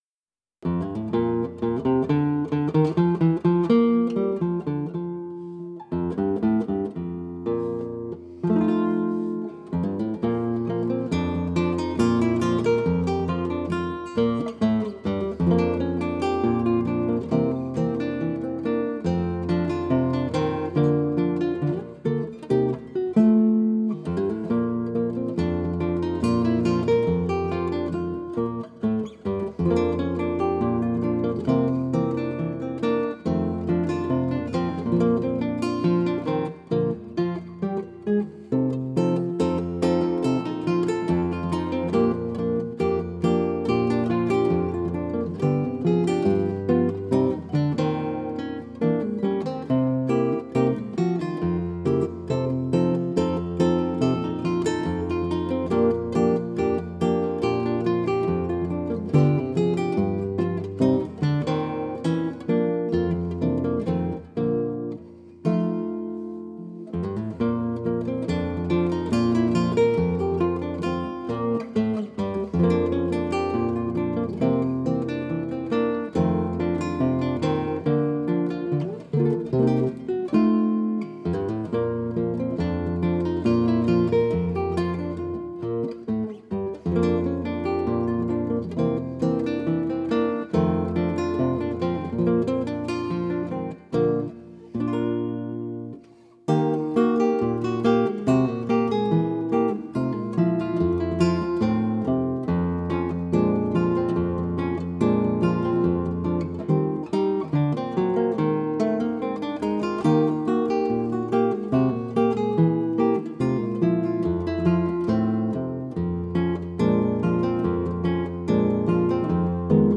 gracefully played
refreshingly natural recording